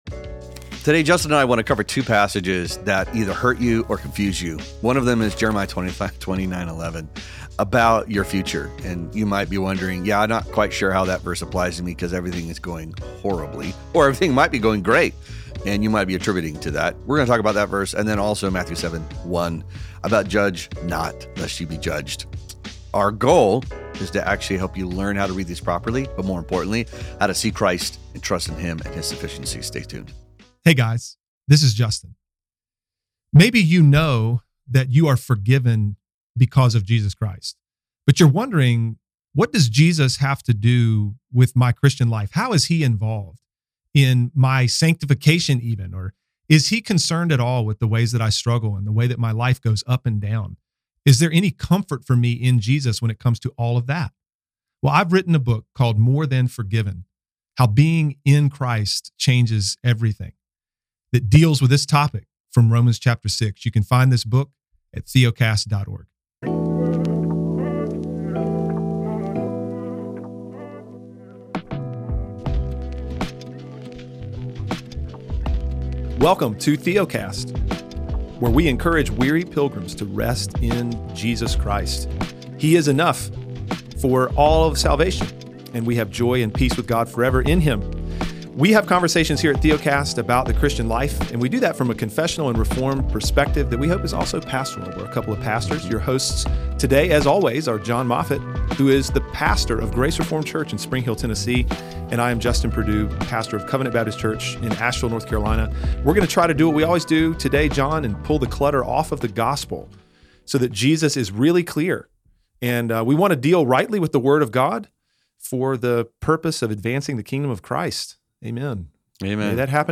This isn’t a debate but a dialogue between three Reformed brothers who…